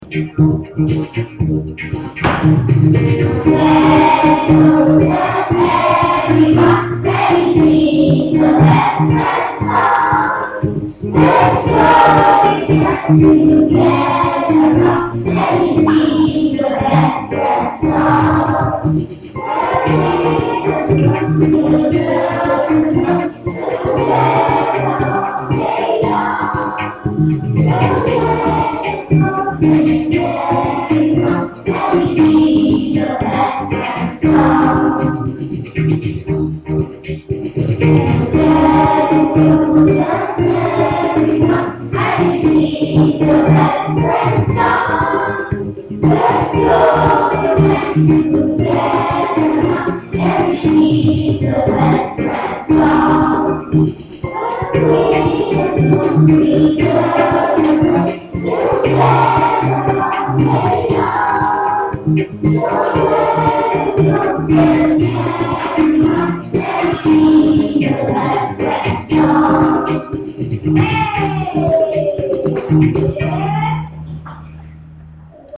scottish-sing-3a-2011.mp3